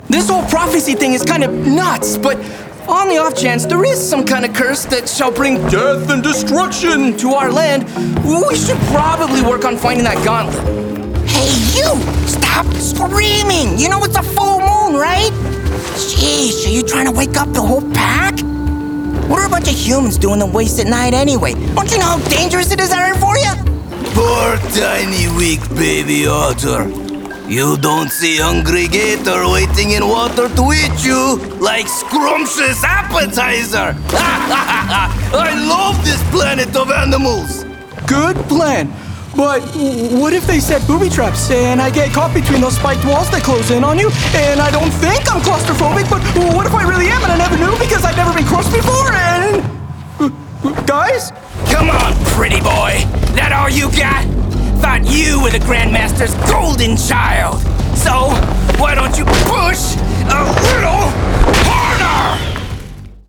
Scots-Puerto Rican Voice Actor
Professionally treated home recording studio with a noise floor of -60db